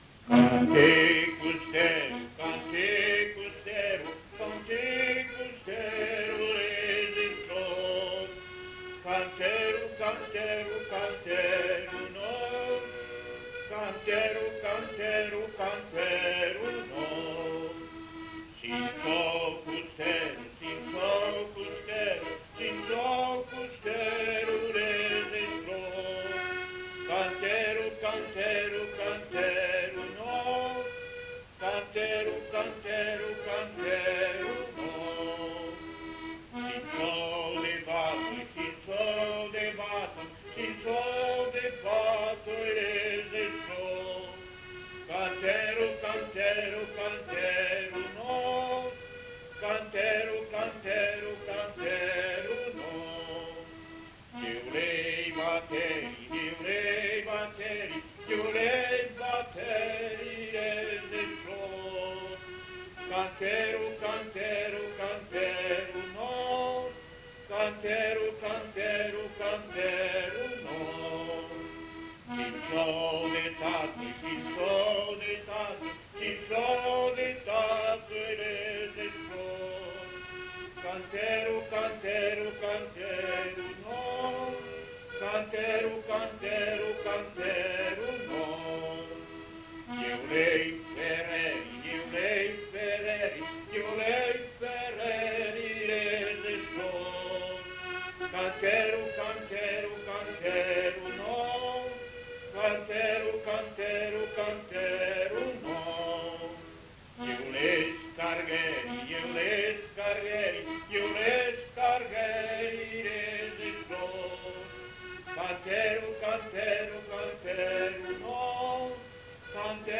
Lieu : Villardonnel
Genre : chanson-musique
Type de voix : voix d'homme
Production du son : chanté
Instrument de musique : accordéon diatonique
Danse : valse